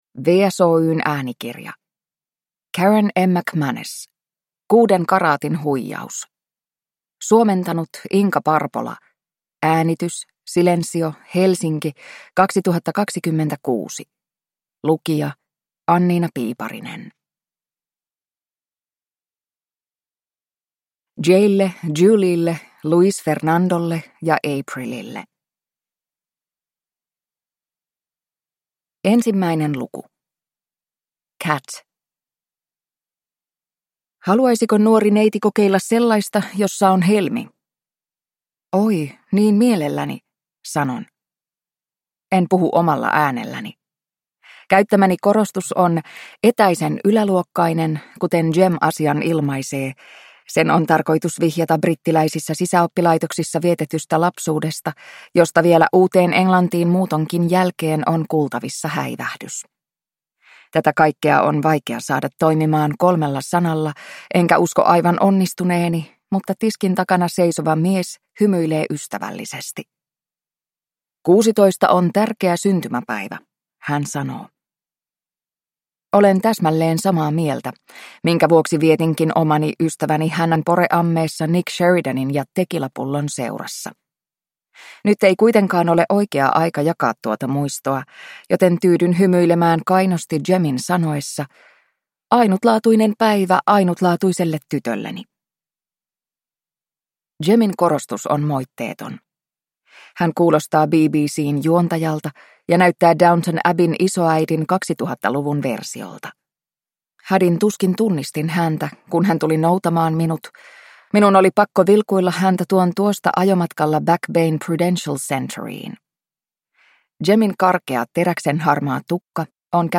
Kuuden karaatin huijaus (ljudbok) av Karen M. McManus